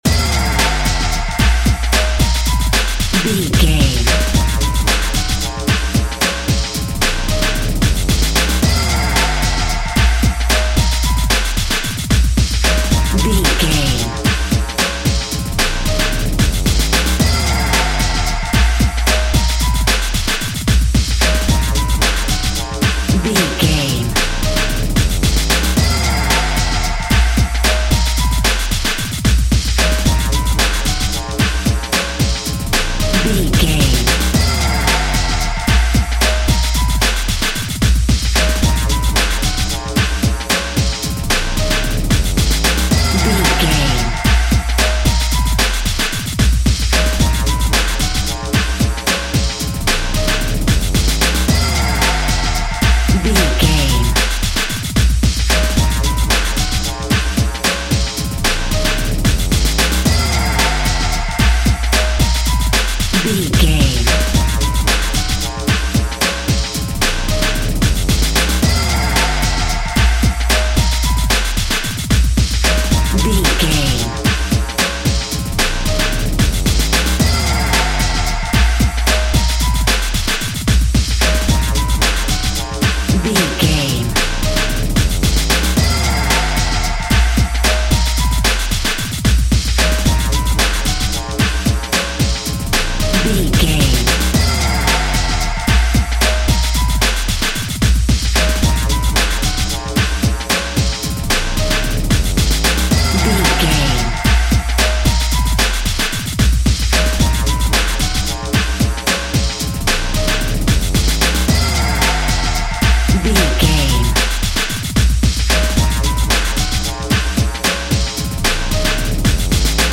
Epic / Action
Fast paced
Ionian/Major
Fast
intense
energetic
driving
dark
aggressive
synthesiser
drums
drum machine
piano
breakbeat
power rock
synth leads
synth bass